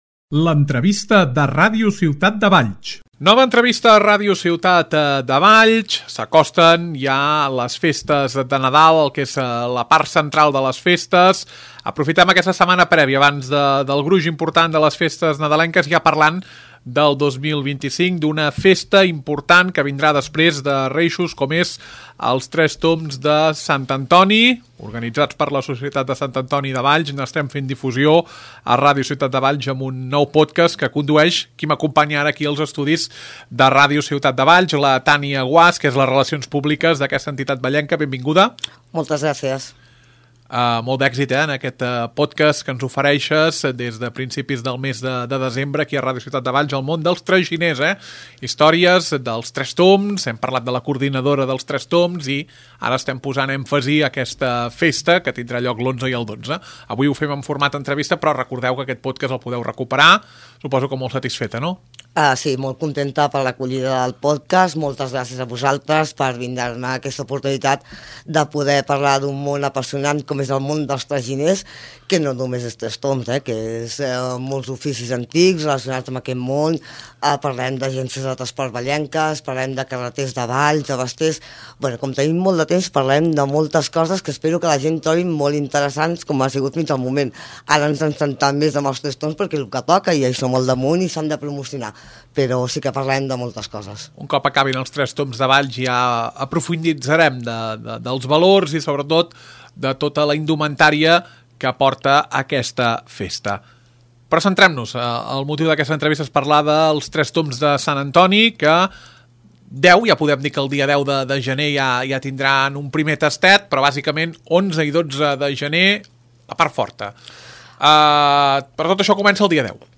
Nova entrevista a Ràdio Ciutat de Valls. Valls celebra els tradicionals Tres Tombs de Sant Antoni del 10 al 12 de gener.